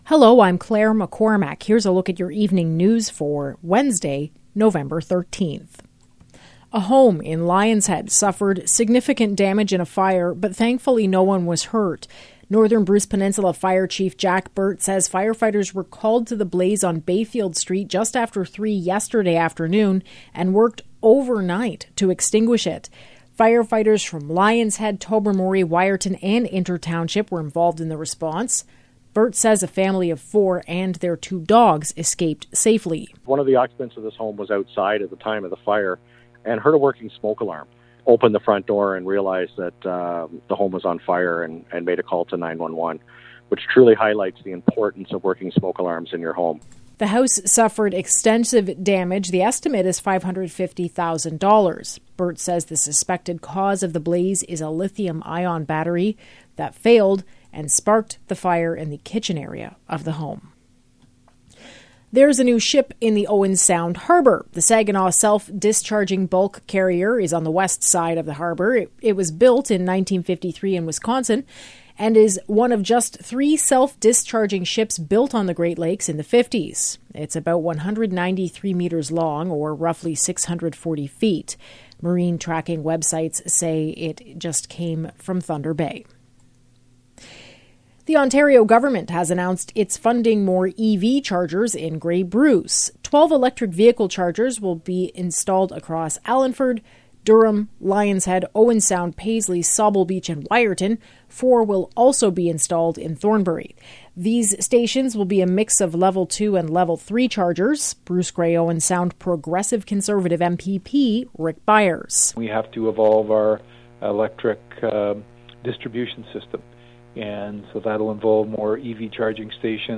Evening News – Wednesday, November 13